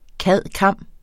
Udtale [ ˈkaðˀˈkɑmˀ ]